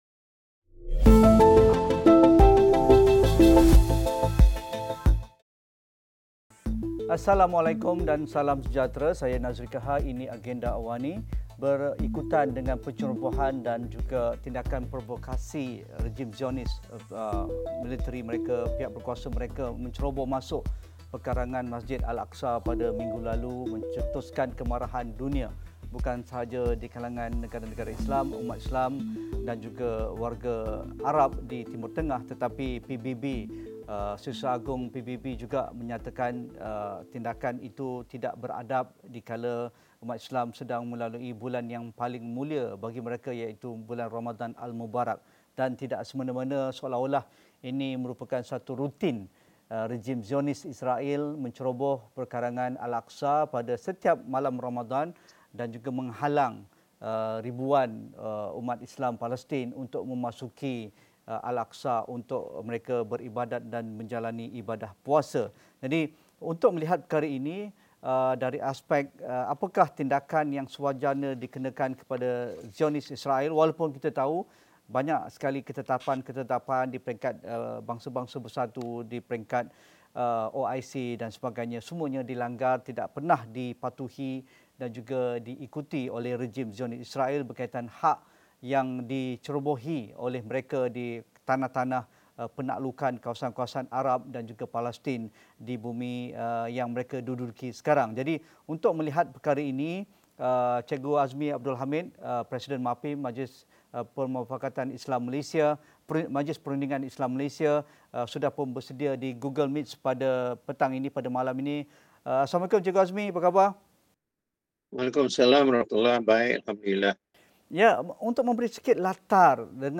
Insiden pencerobohan dan keganasan regim Zionis ini bukan pertama kali berlaku ketika umat Islam Palestin beribadah pada bulan Ramadan. Apa sebenarnya motif ingin dicapai dengan tindakan provokatif ini? Diskusi 8.30 malam